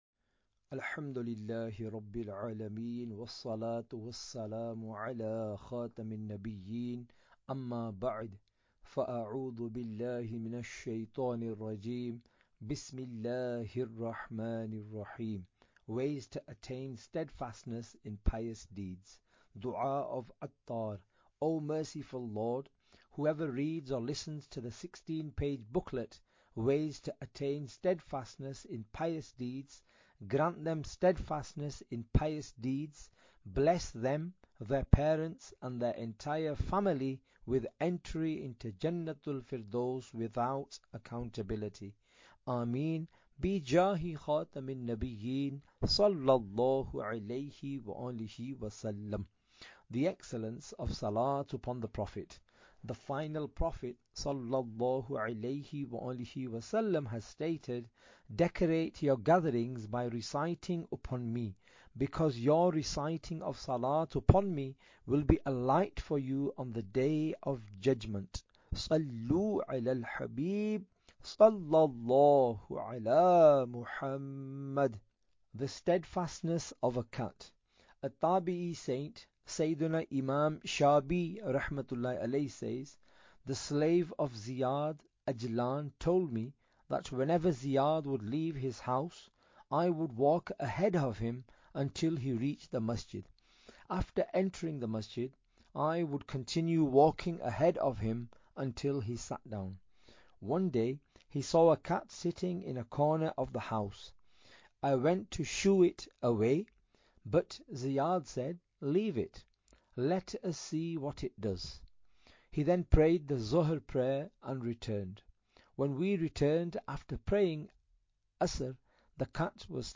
Audiobook - Ways To Attain Steadfastness in Pious Deeds (English)